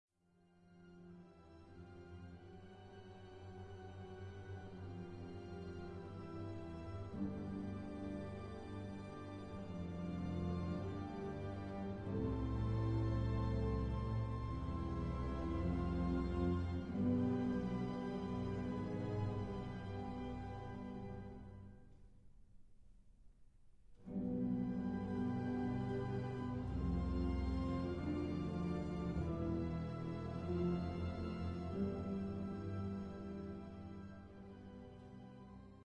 Symphony No. 11 in G minor Op. 103
Adagio 16:46